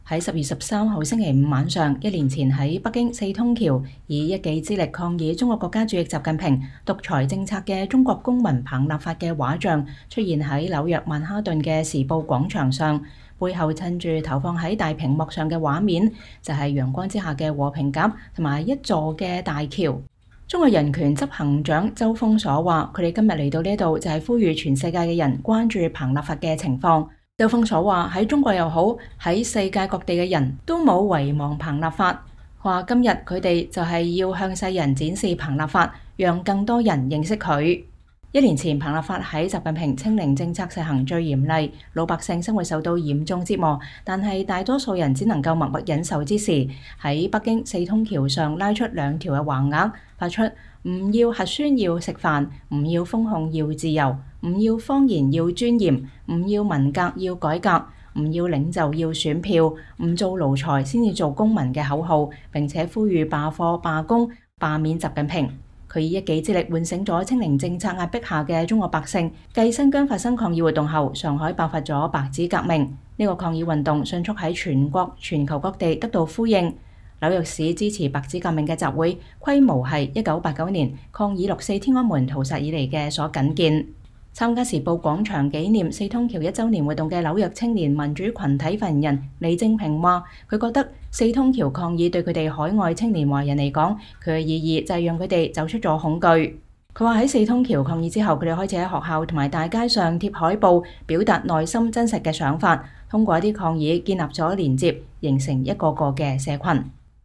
紐約時報廣場：紀念彭立發四通橋事件一週年